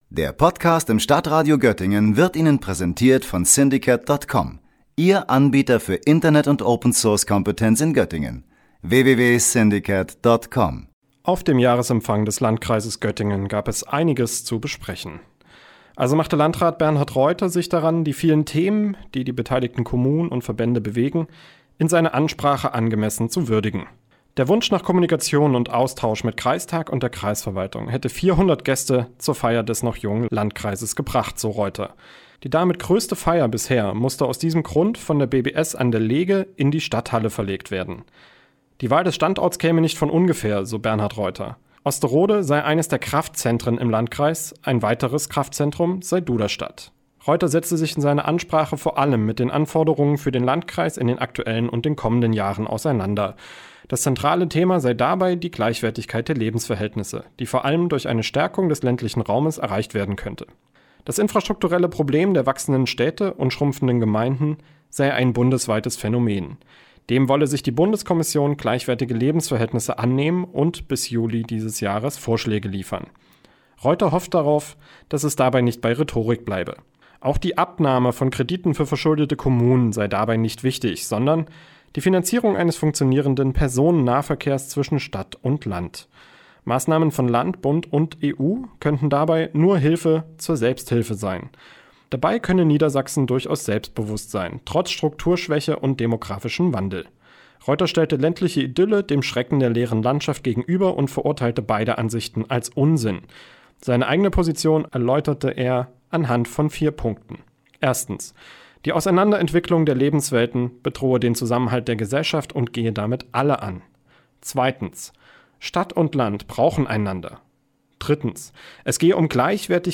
Am Dienstagabend lud der Landkreis Göttingen zum Jahresempfang für das Jahr 2019. Landrat Bernhard Reuter hielt dort eine Ansprache, in der er die aktuellen und kommenden Probleme der ländlichen Umgebung Göttingens skizzierte.